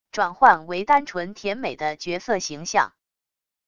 转换为单纯甜美的角色形象wav音频